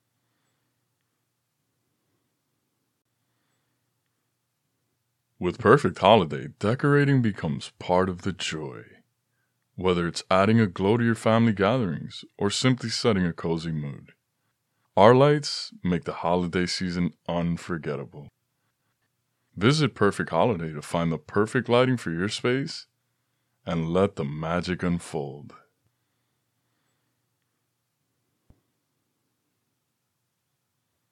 Holiday Lighting Company Online Ad_Closing Scene
Young Adult
I have a naturally strong, clear voice with a broad range. My voice is primarily warm and smooth, yet authoritative with an intellectual subtleness.